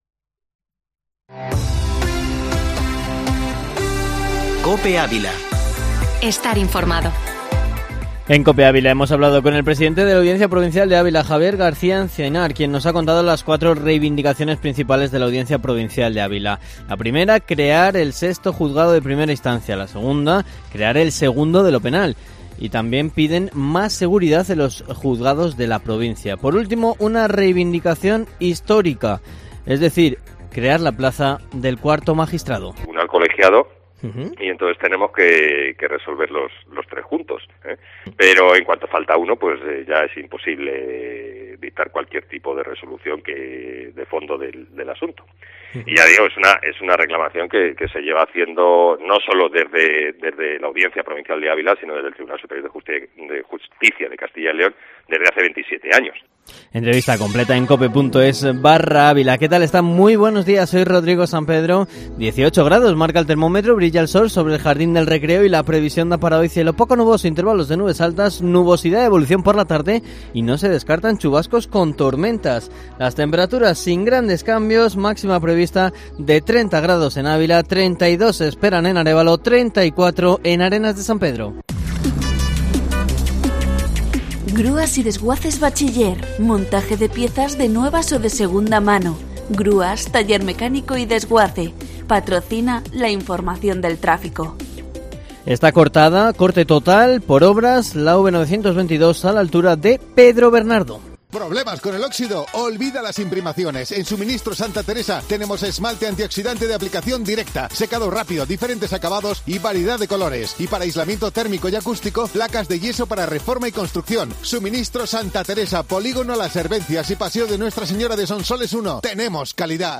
Informativo matinal Herrera en COPE Ávila / 11-junio
Informativo matinal Herrera en COPE Ávila, información local y provincial